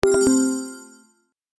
Bright Message Alert, Notification, Tone 2 Sound Effect Download | Gfx Sounds
Bright-message-alert-notification-tone-2.mp3